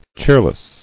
[cheer·less]